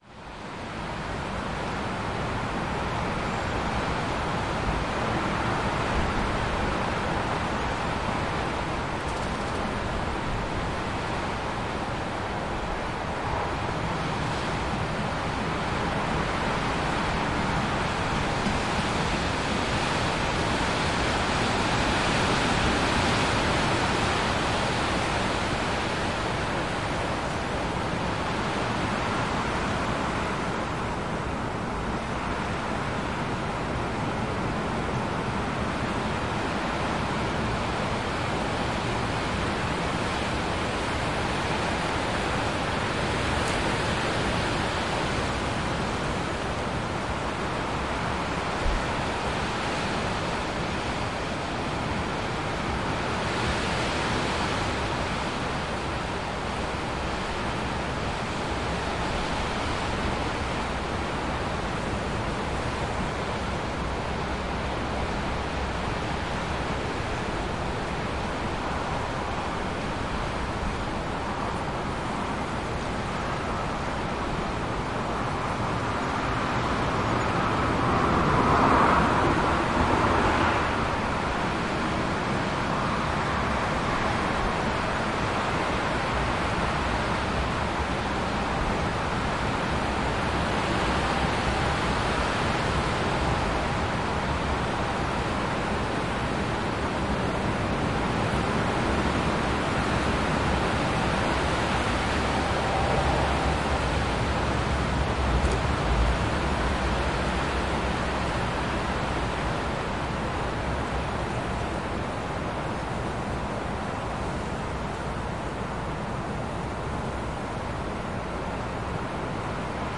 描述：现场记录强风和沙沙作响的树木。过往车辆的偶然噪音。在午夜的轻的交通在乡下公路。 地点：南威尔士，UKTech：Tascam DR40，无需加工